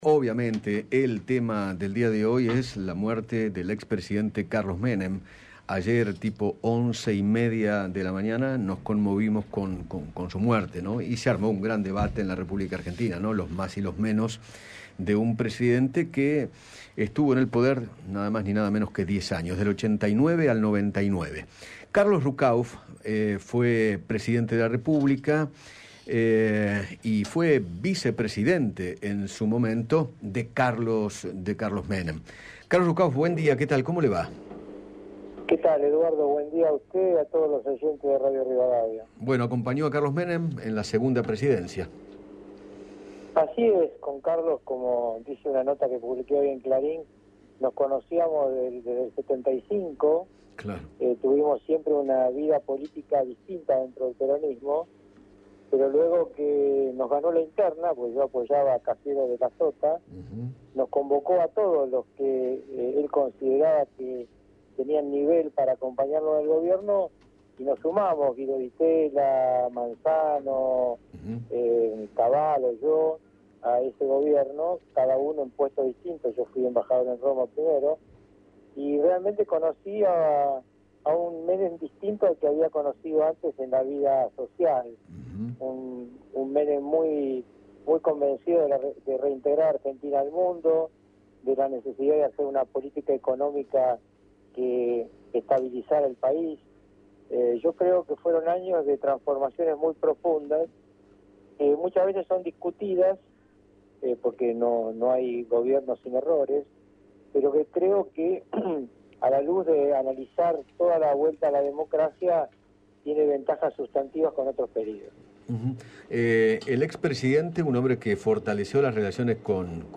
Carlos Ruckauf, vicepresidente durante el segundo mandato de Carlos Menem, dialogó con Eduardo Feinmann sobre la muerte del ex presidente, quien falleció este domingo a la mañana en el Sanatorio Los Arcos.